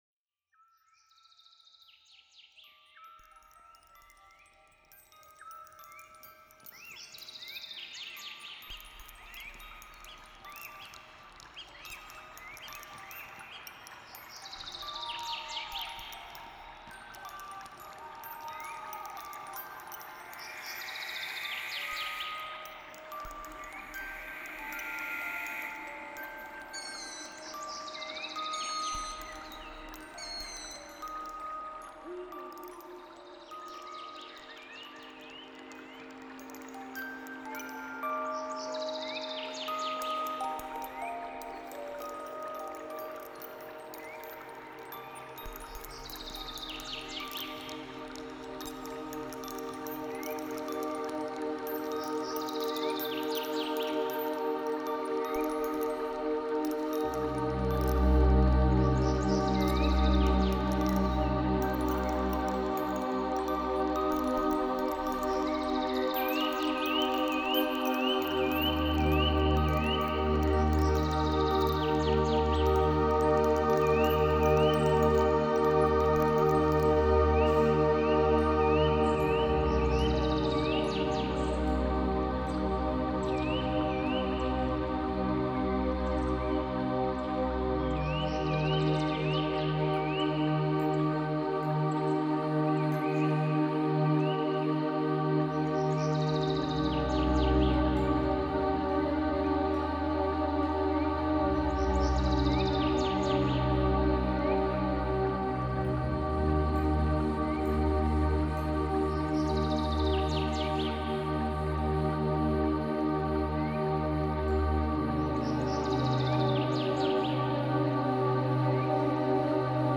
432 hrz - ACCORDATURA | VIBRAZIONI D'ARMONIA
Deserved_Relaxation_432Hz.mp3